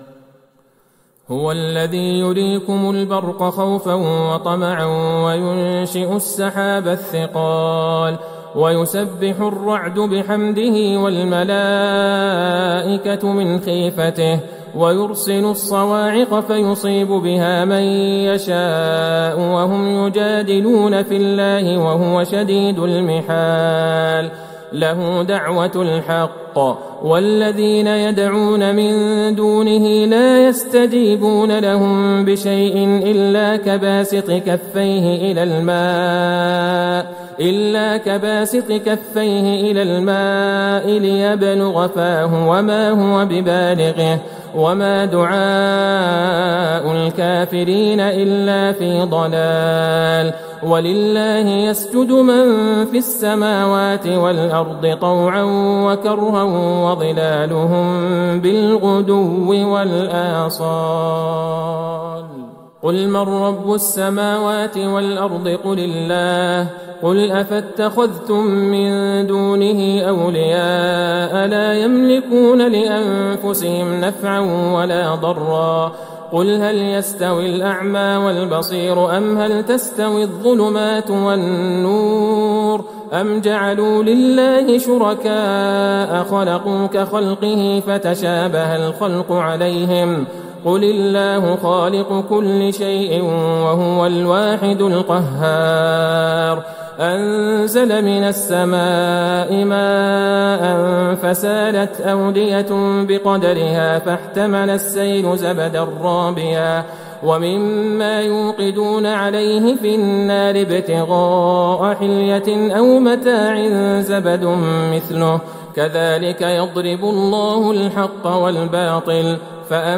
ليلة ١٧ رمضان ١٤٤١هـ من سورة الرعد { ١٢-٤٣ } وإبراهيم { ١-١٢ } > تراويح الحرم النبوي عام 1441 🕌 > التراويح - تلاوات الحرمين